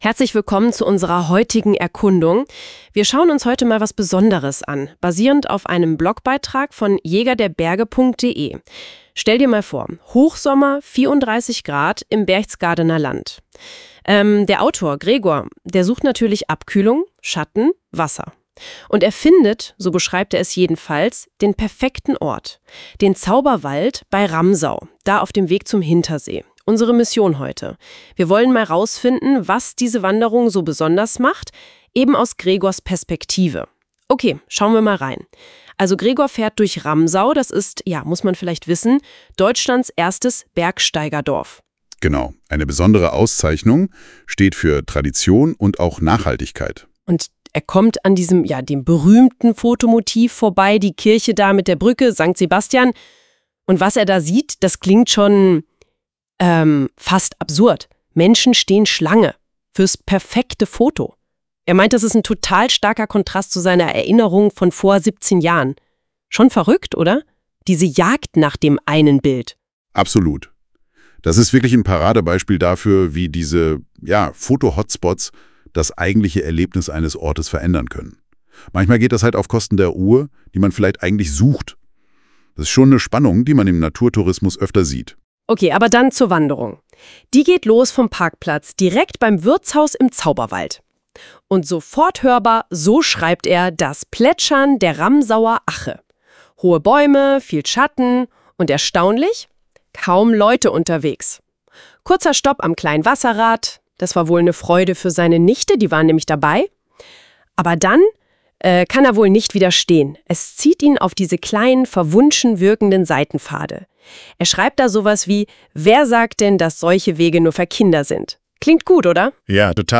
*AI Zusammenfassung